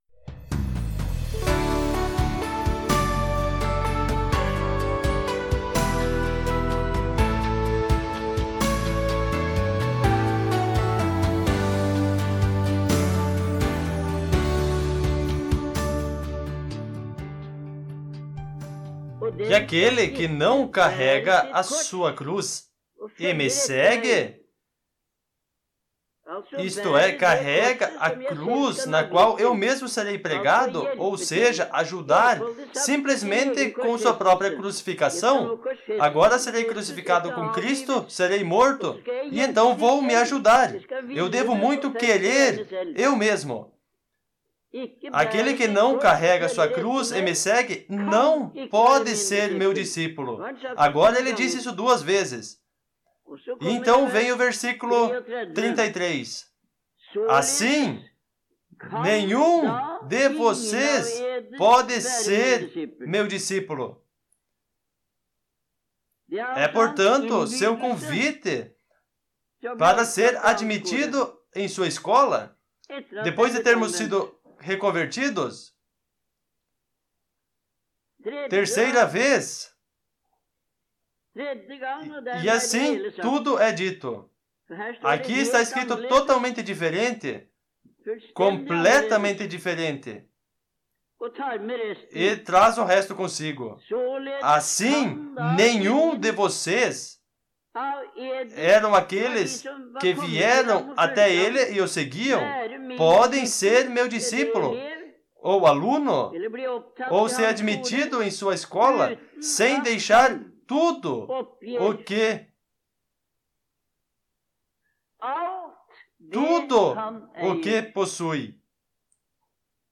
Taleutdrag fra januar 1969